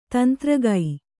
♪ tantragai